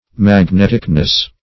Magneticness \Mag*net"ic*ness\, n.
magneticness.mp3